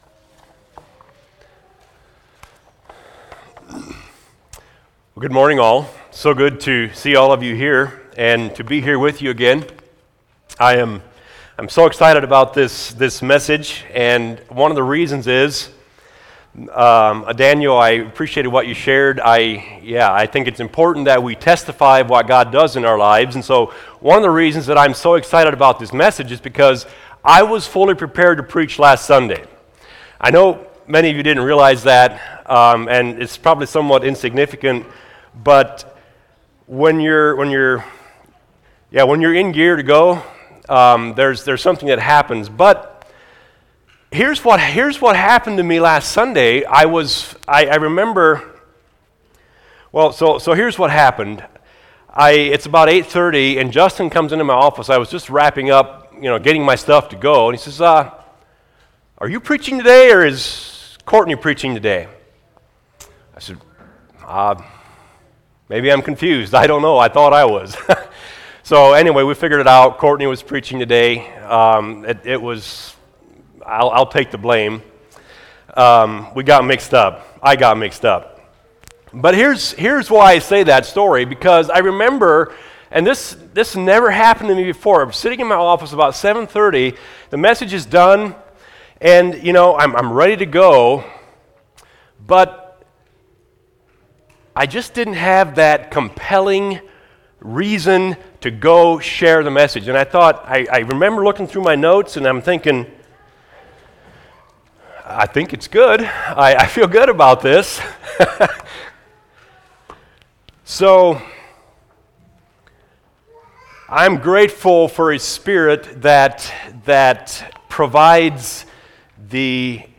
Home Sermons Good Or God?